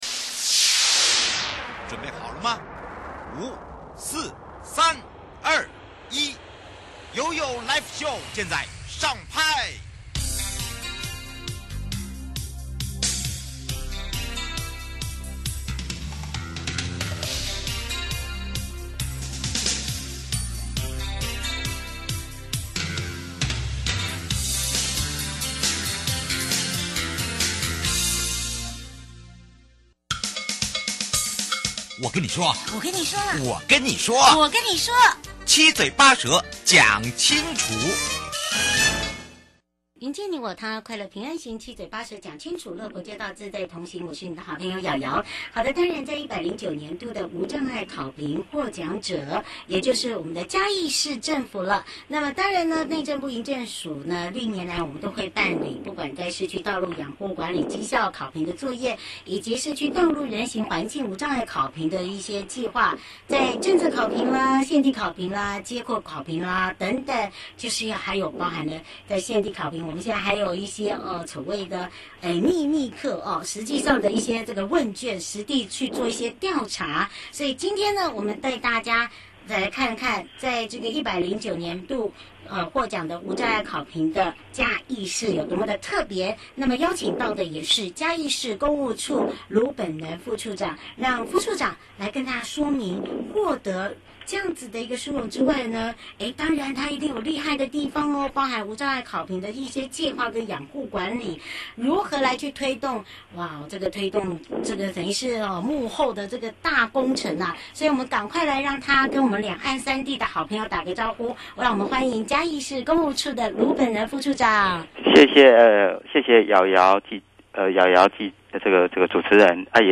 受訪者： 109 年度無障礙考評獲獎者(嘉義市政府)(上集) 是不是可以跟我們來分享針對這個計畫嘉義縣有哪一些